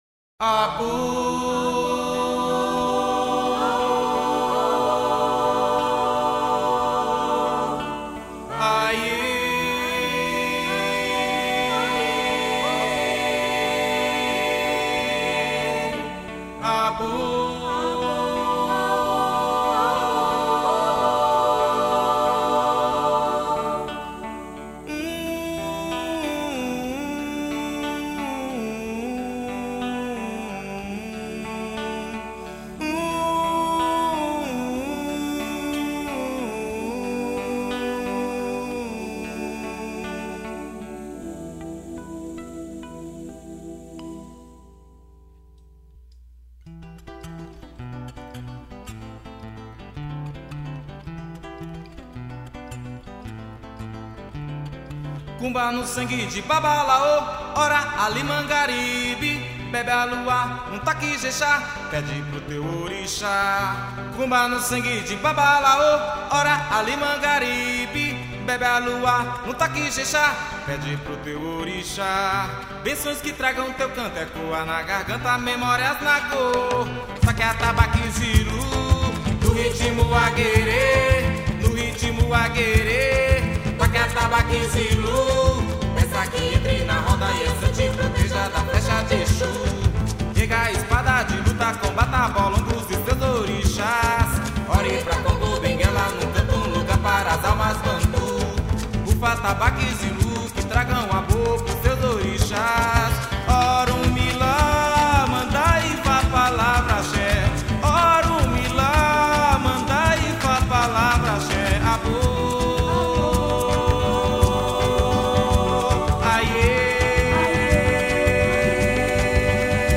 244   03:51:00   Faixa:     Canção Afro